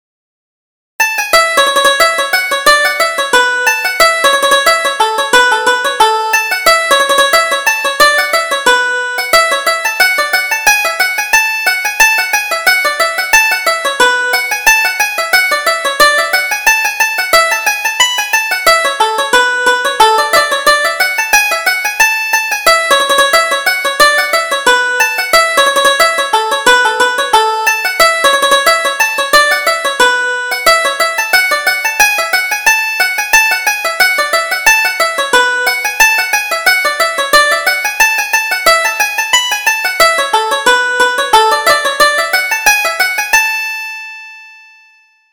Reel: The Miller's Daughter